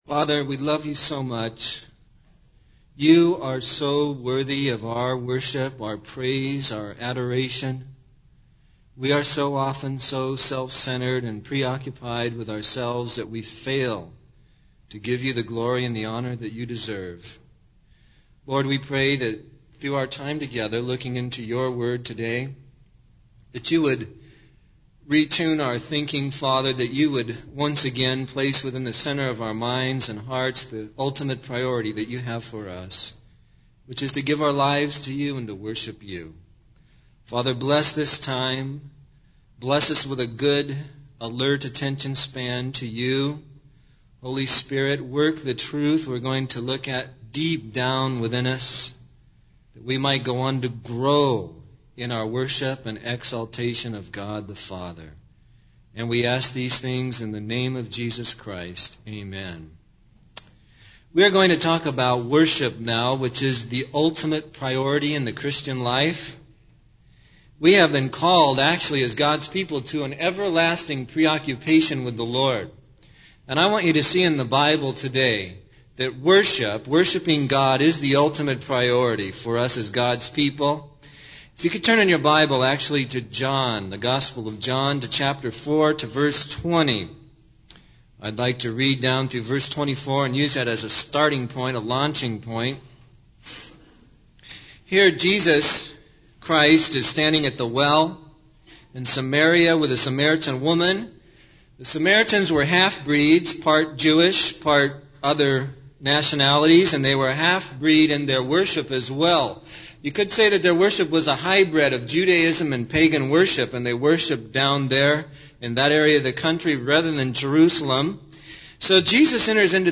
In this sermon, the speaker emphasizes the importance of worship in the context of the end times and the imminent return of Jesus Christ. He states that worship is the ultimate purpose of redemption and the ultimate focus of the Bible.